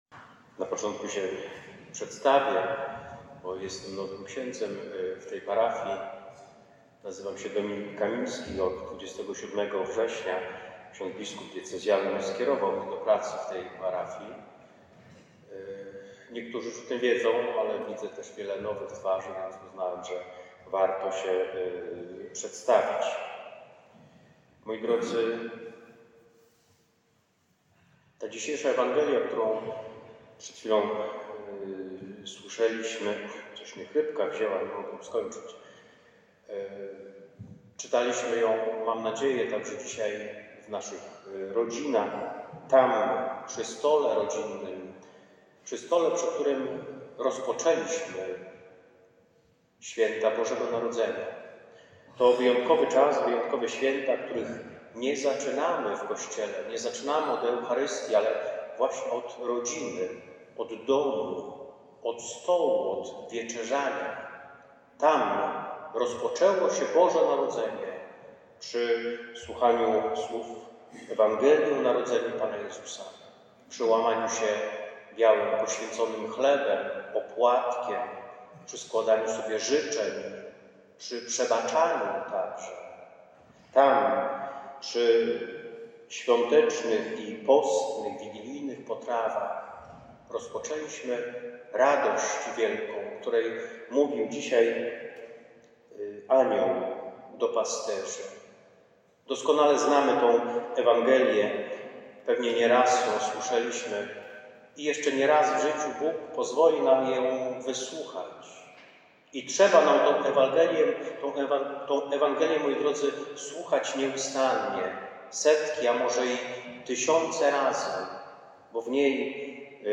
W noc z 24 grudnia na 25 grudnia jak co roku spotkaliśmy się na Mszy Św. Pasterskiej.